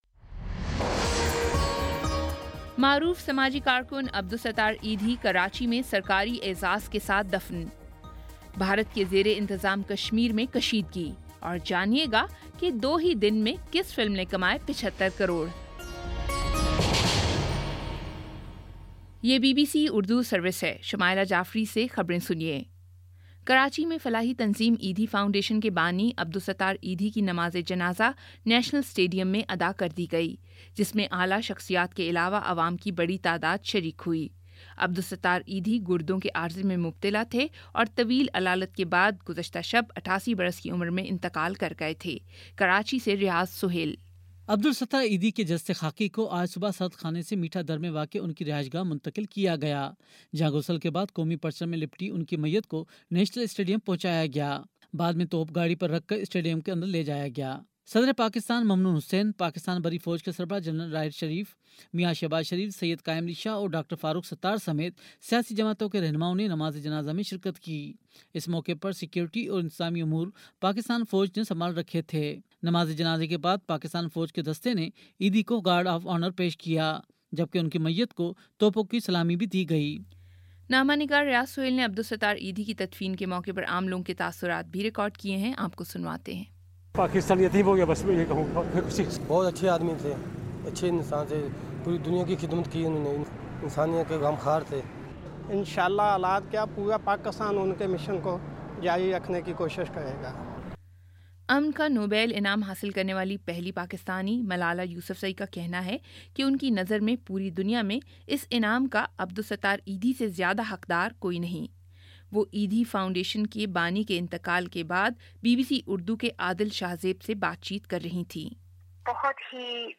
جولائی 09 : شام چھ بجے کا نیوز بُلیٹن